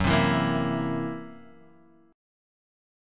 F#7b5 chord {2 3 2 3 x 0} chord